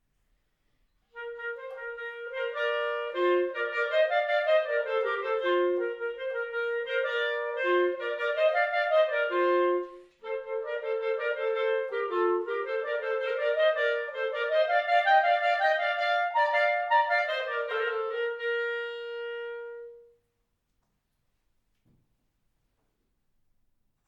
Besetzung: 2 Klarinetten